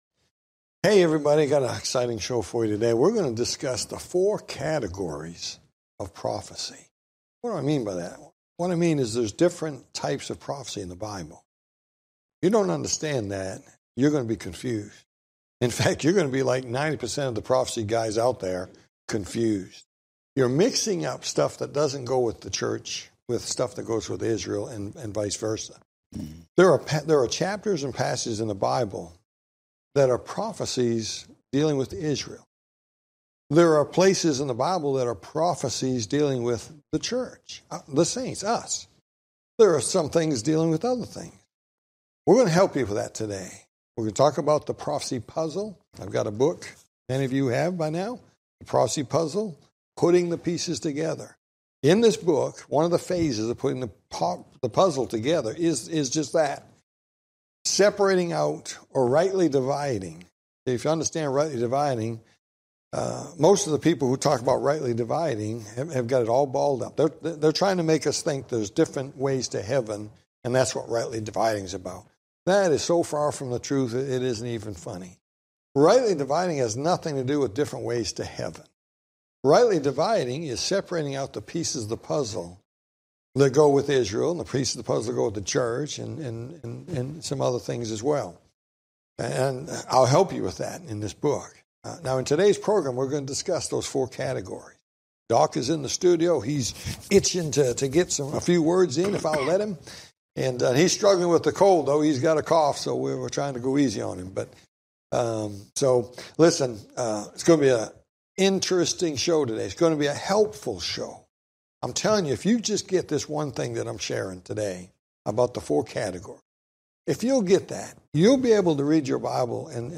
Talk Show Episode, Audio Podcast, Prophecy In The Spotlight and Prophecy Puzzle And Rumors Of War, Part 2 on , show guests , about Prophecy Puzzle And Rumors Of War, categorized as History,News,Politics & Government,Religion,Society and Culture,Theory & Conspiracy